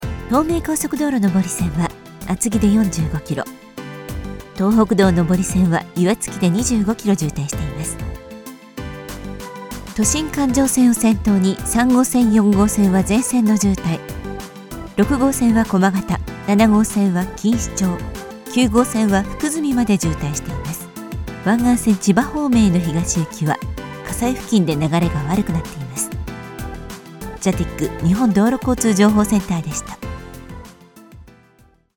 Jovial
Trustworthy
Warm